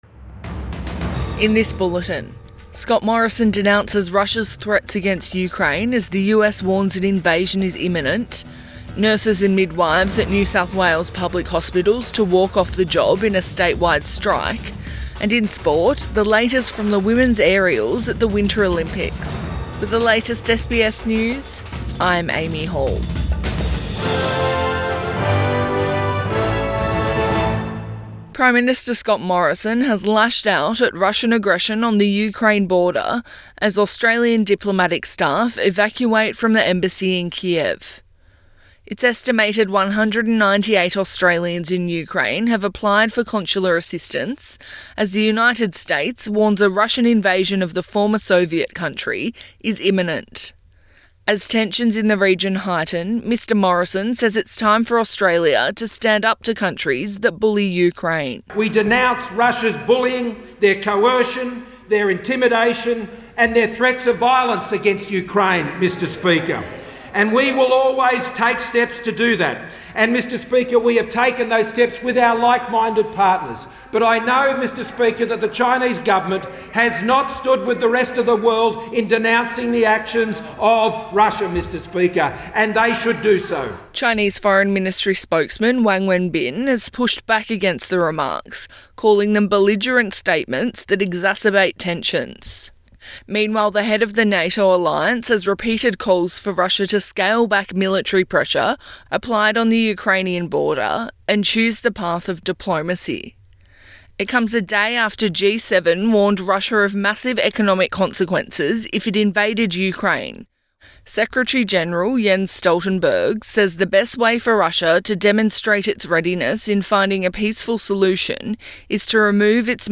AM bulletin 15 February 2022